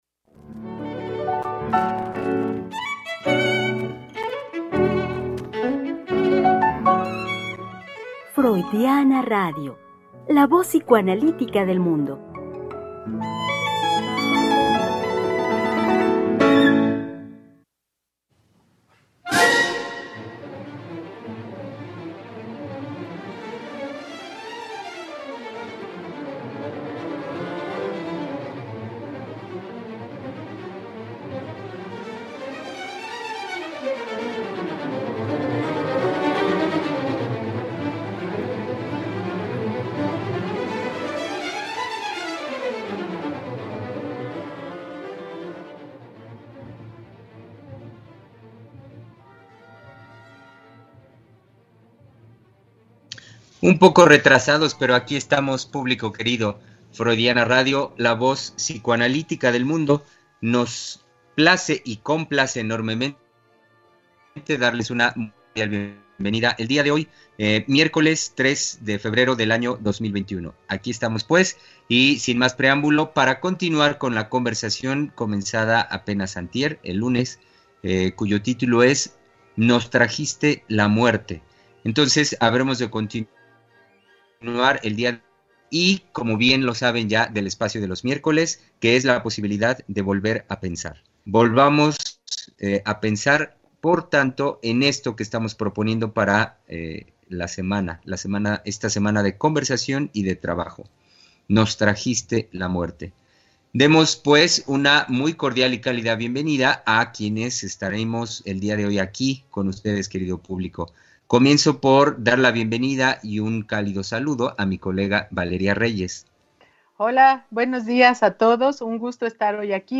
Conversación con las psicoanalistas